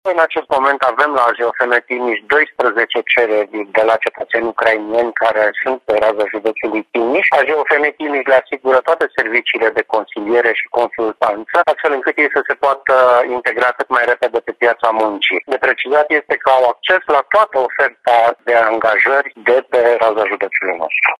Subprefectul Sorin Ionescu spune că refugiații de război beneficiază de asistență din partea autorităților pentru cazare, hrană, ajutor psihologic sau școală pentru copii.